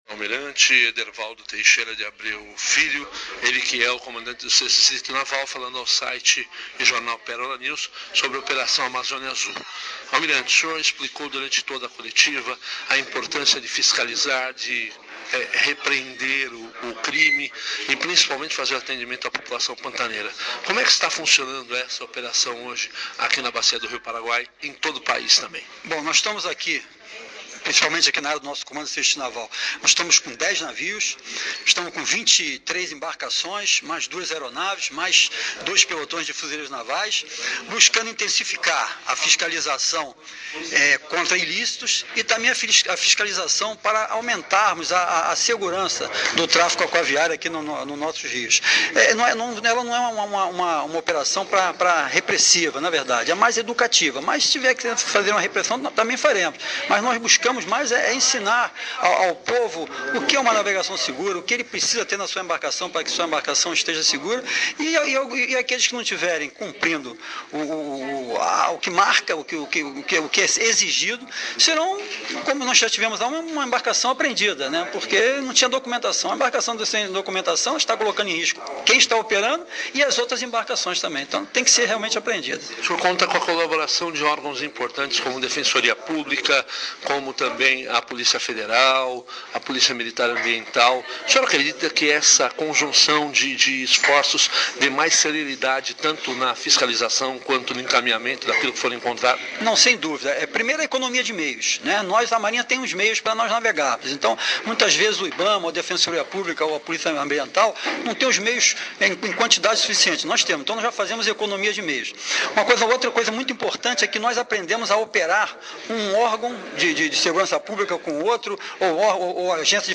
Entrevista com o comandante do 6º Distrito Naval de Ladário